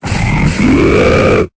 Cri d'Excavarenne dans Pokémon Épée et Bouclier.